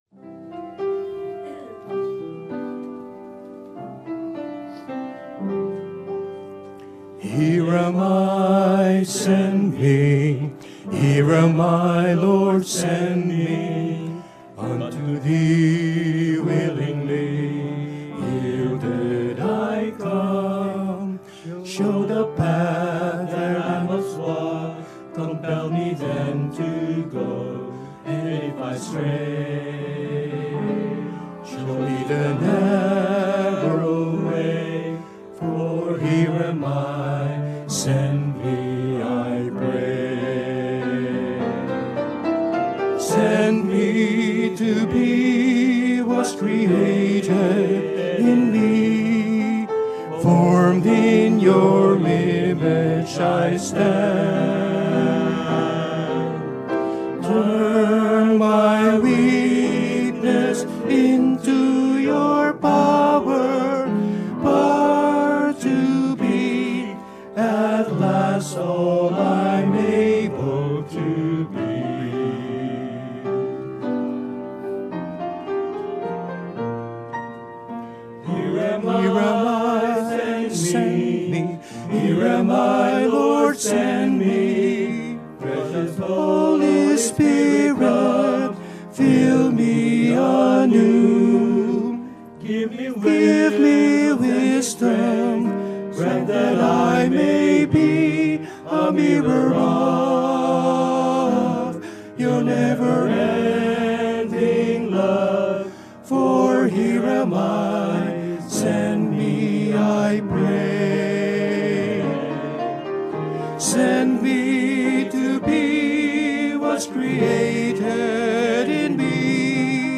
Special Music